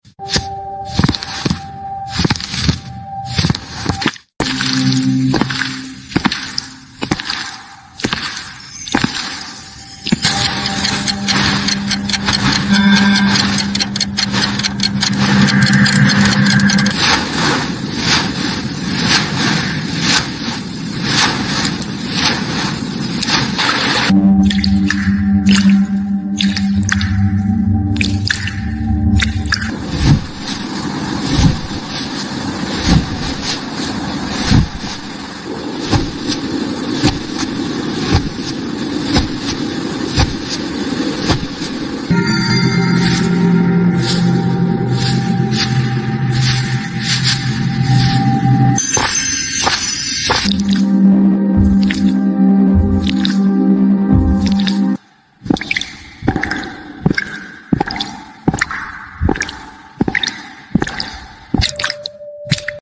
ASMR, Come And Experience The Sound Effects Free Download